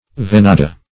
Venada \Ve*na"da\, n.